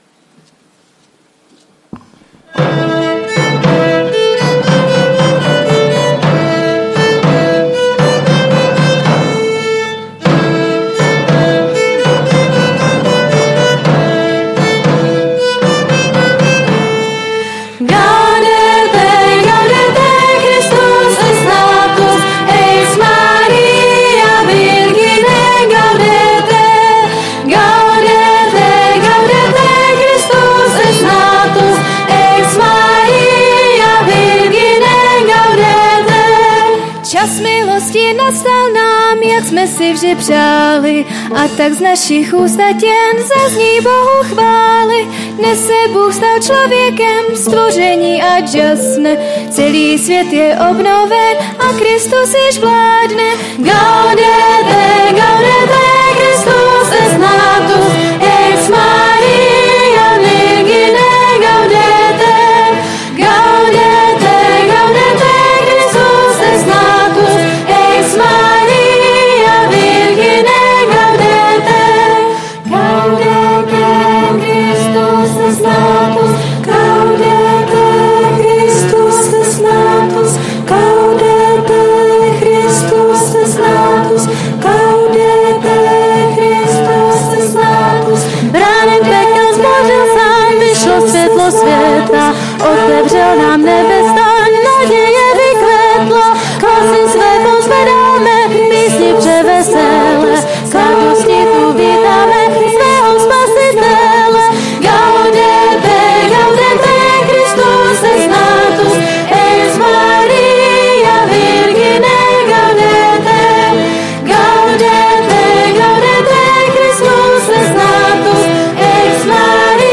Kategorie: Půlnoční bohoslužby